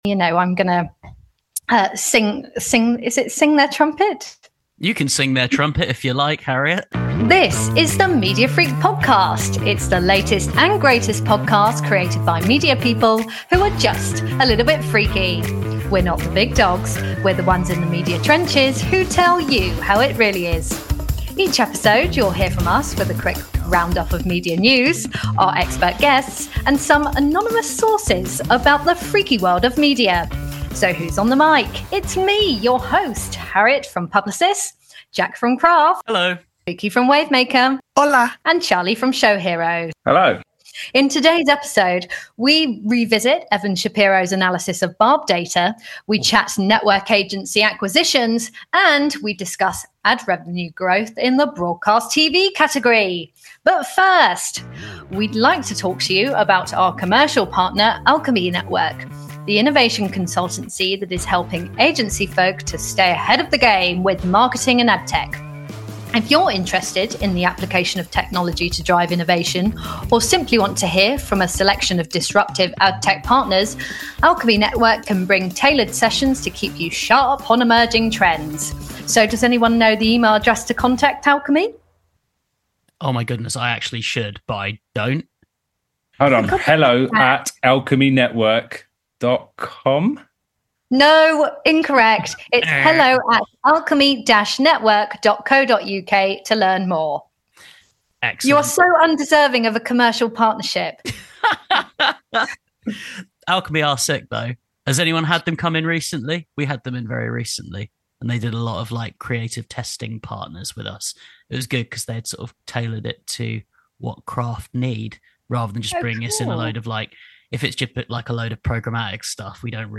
This is the marketing and advertising podcast presented by a team from across the industry. Each episode will feature news and discussion from across our business.